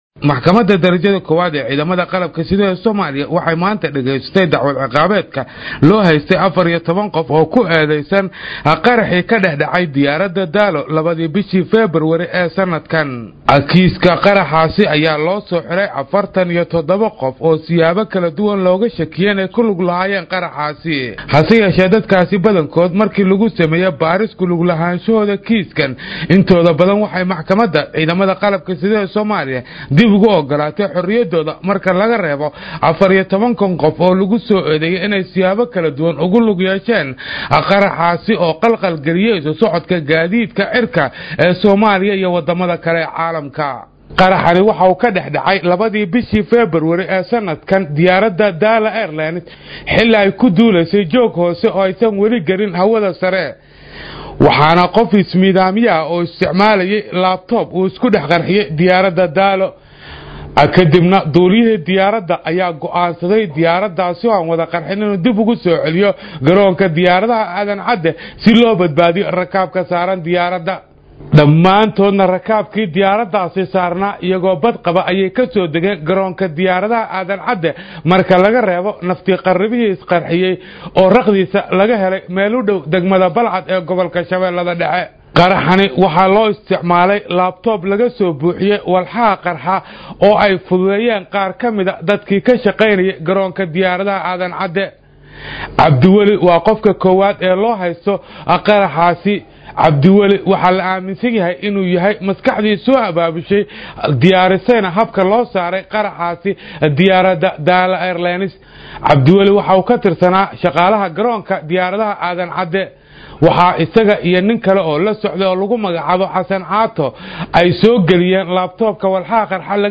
warbixinta